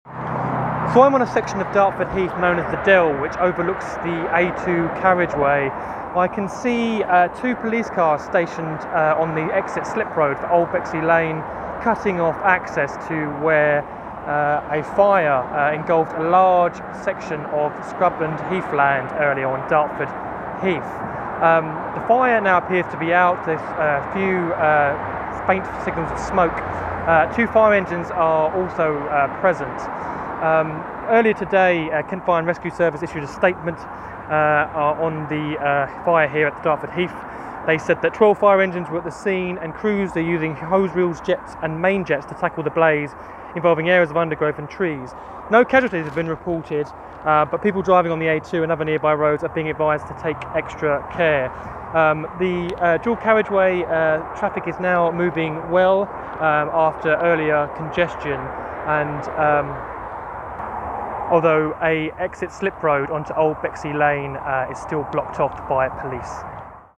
is near the scene of a field fire on Dartford Heath